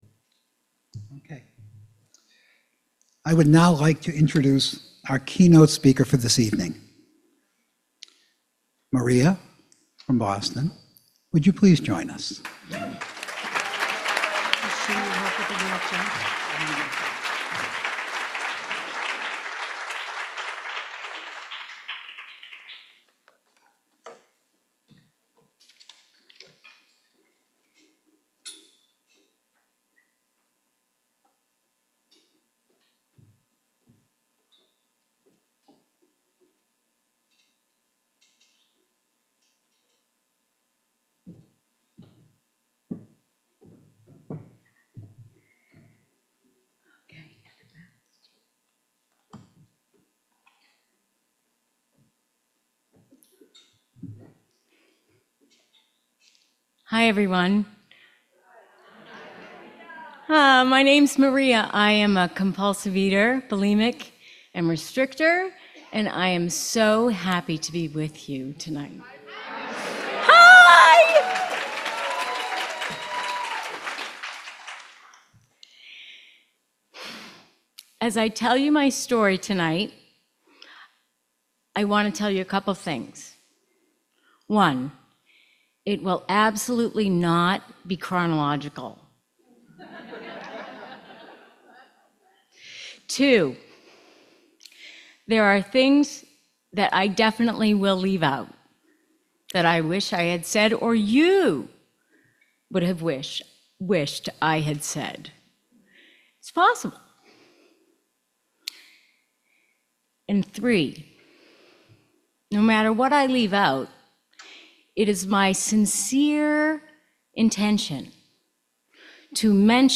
This keynote address was given at the 2024 OA Region 6 convention, held in October in Nashua, NH, US.